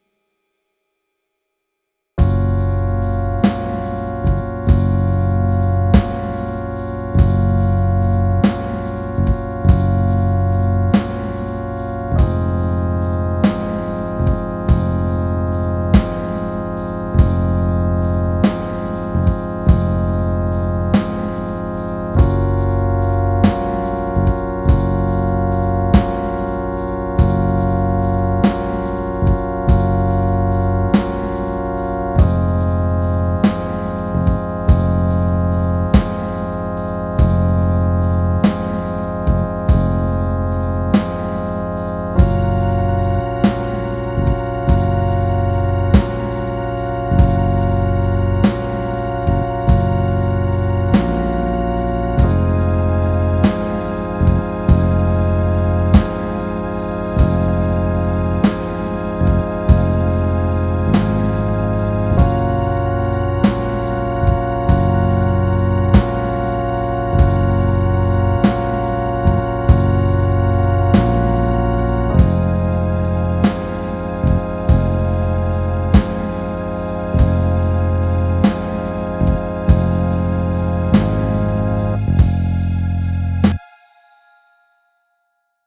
This one is like 2 chords over and over again.
I was gonna sing on it but I couldn't think of anything to sing.